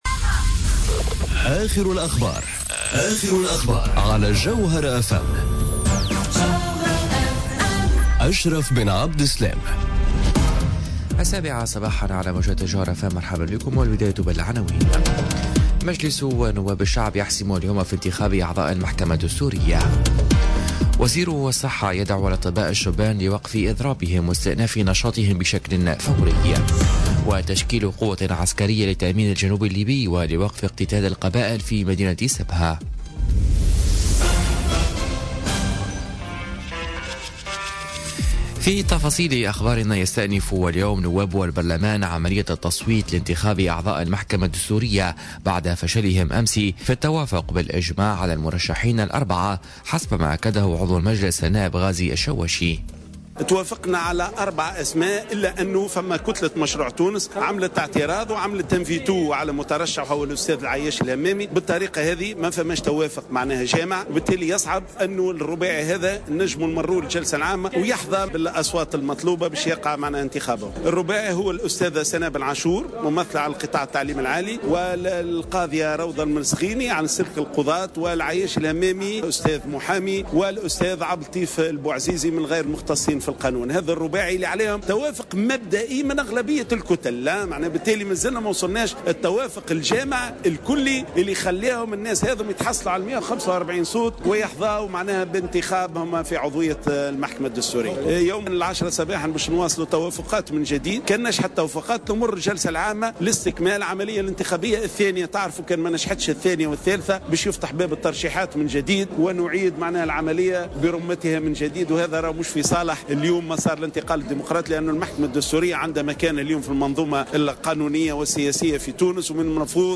نشرة أخبار السابعة صباحا ليوم الإربعاء 13 مارس 2018